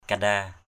kanda.mp3